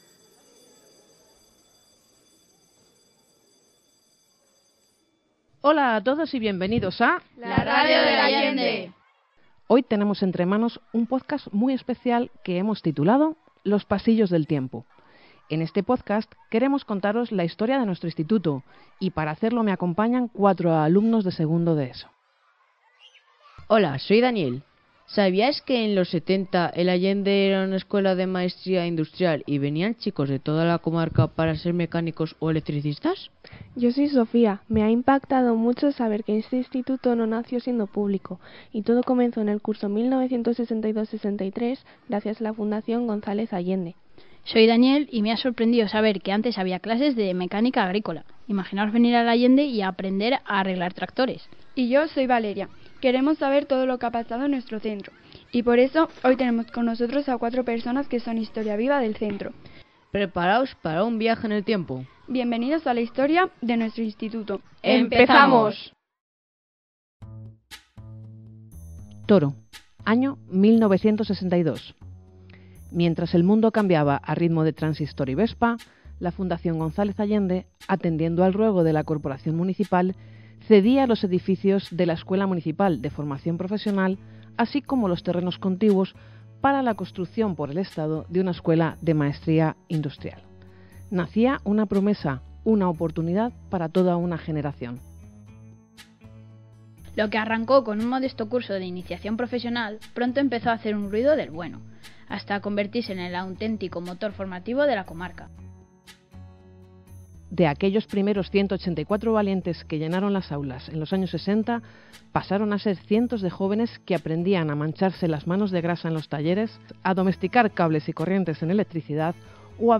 La Radio del Allende » Historia del IES Gonz�lez Allende La Radio del Allende Historia del IES Gonz�lez Allende Historia de nuestro centro Aquí podrás escuchar la historia del IES González Allende a través de diversas entrevistas Historia del IES González Allende Enlaces Institucionales